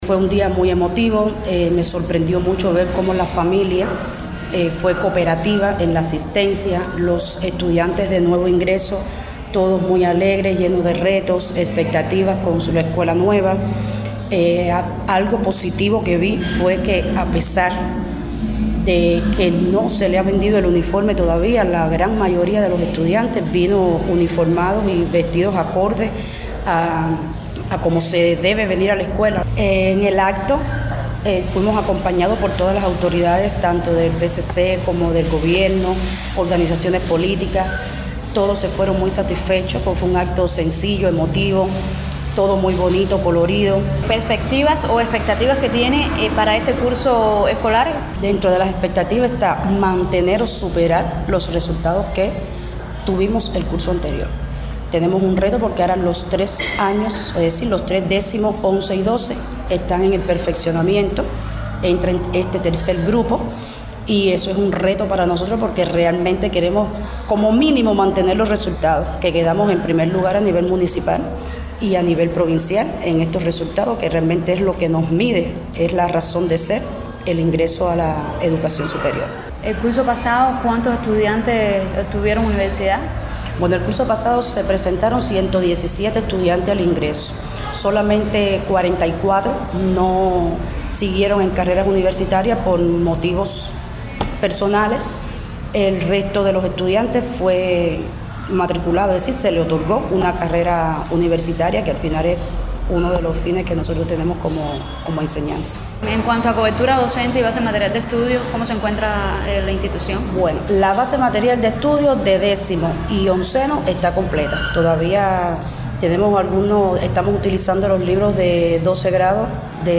Con una emotiva gala político-cultural, alumnos y profesores se reencontraron este lunes, para dar inicio al curso escolar 2025-2026.